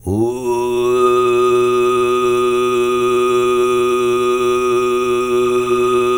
TUV2 DRONE08.wav